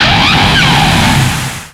Cri de Torterra dans Pokémon X et Y.